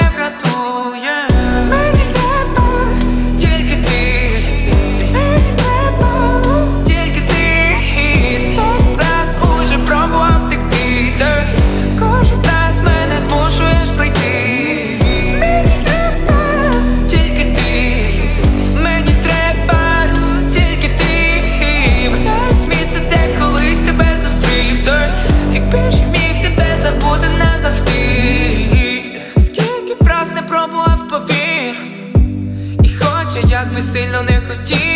Жанр: Соул / R&b / Украинские